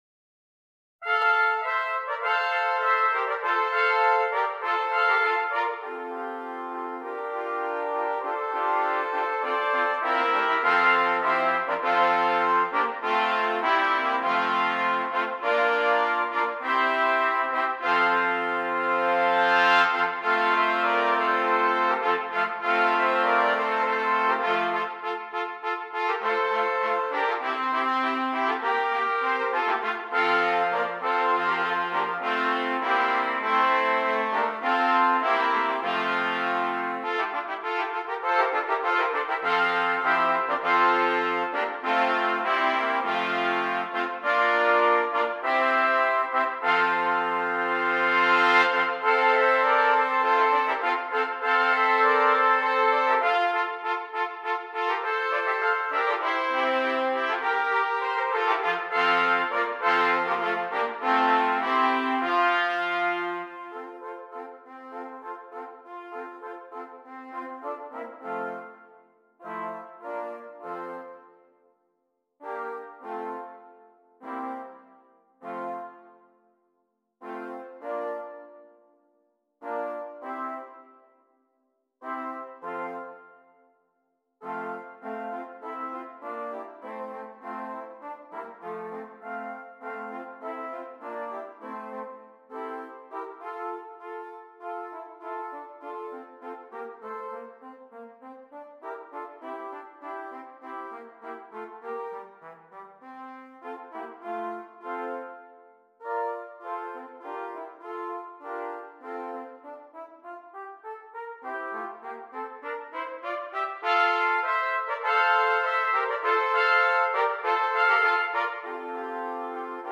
6 Trumpets
Traditional